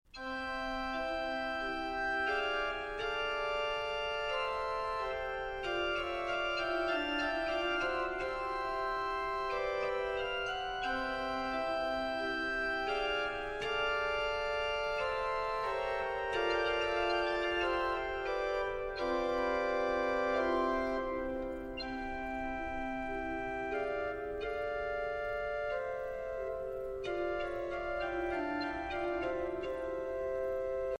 Festliche Advents- und Weihnachtsmusik
Vocal- und Orgelmusik Neuerscheinung 2010